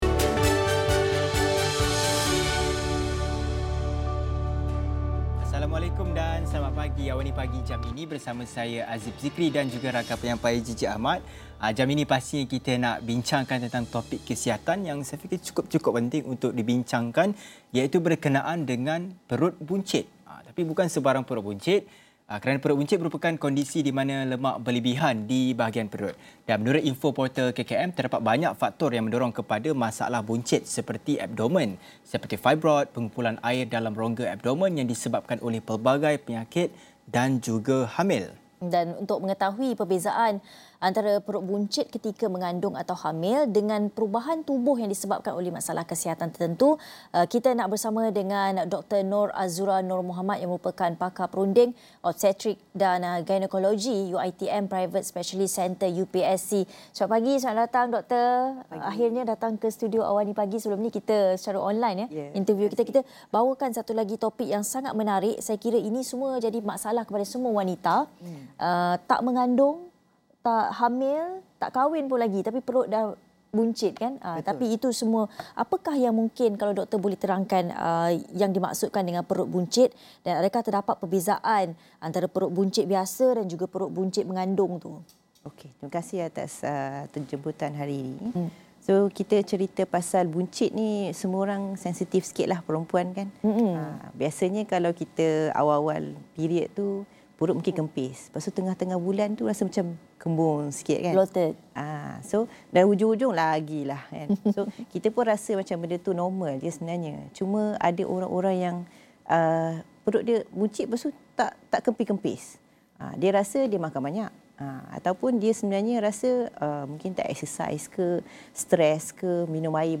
Diskusi mengenai isu kesihatan